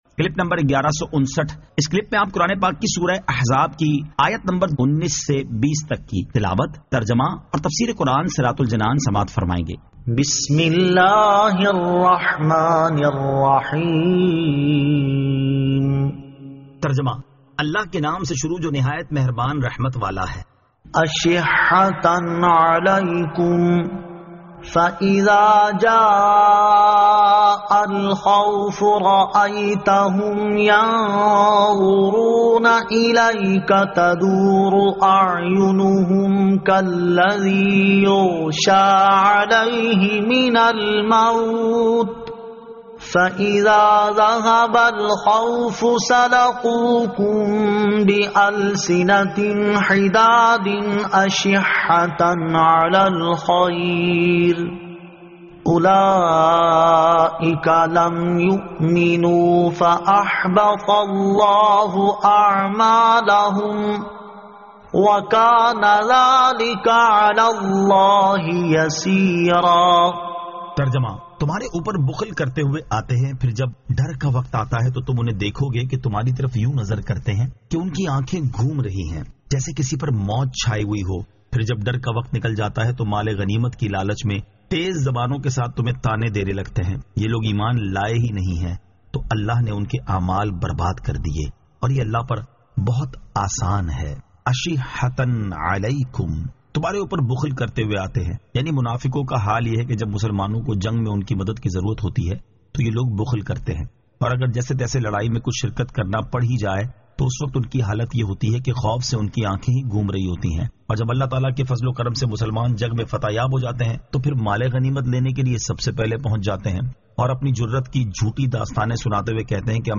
Surah Al-Ahzab 19 To 20 Tilawat , Tarjama , Tafseer